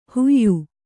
♪ huyyu